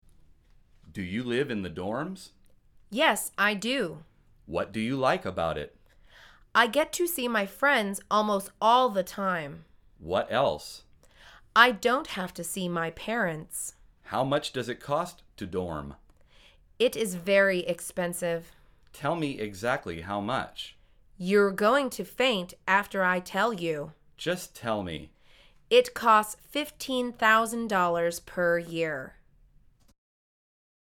مجموعه مکالمات ساده و آسان انگلیسی – درس شماره سیزدهم از فصل زندگی محصلی: زندگی در خوابگاه